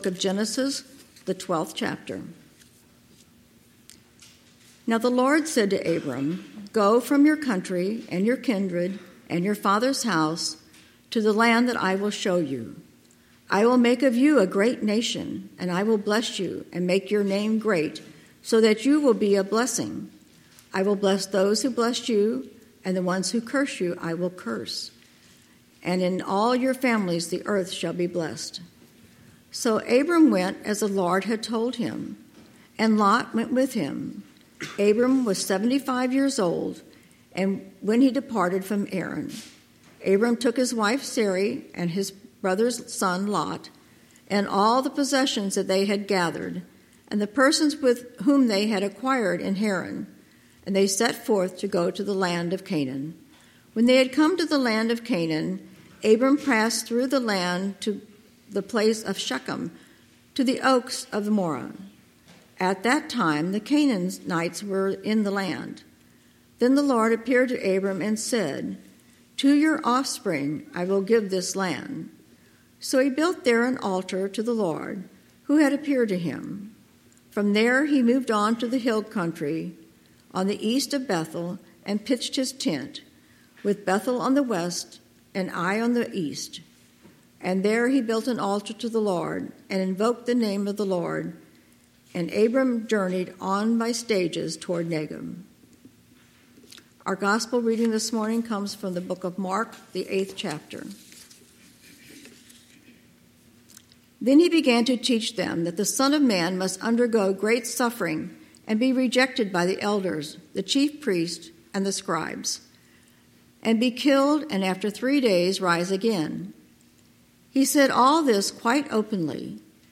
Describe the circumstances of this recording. Mark 8:31-38 Service Type: Sunday Morning « Fearless Fearless